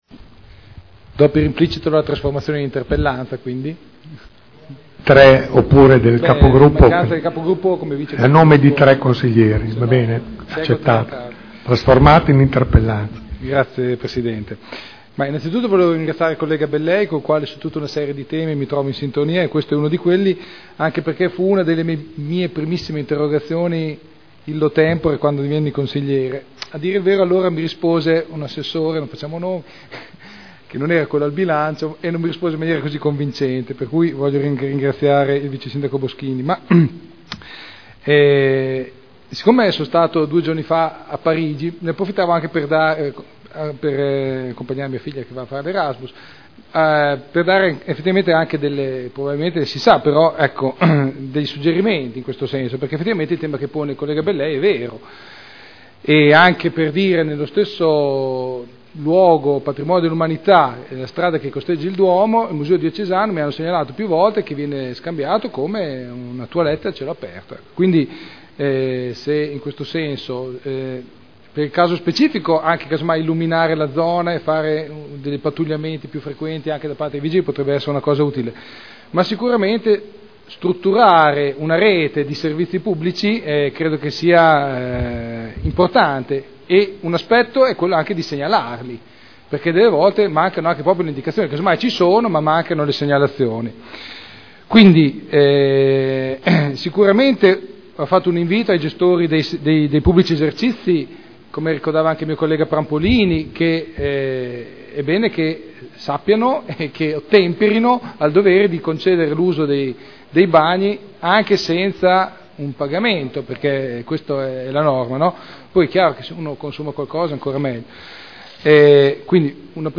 Enrico Artioli — Sito Audio Consiglio Comunale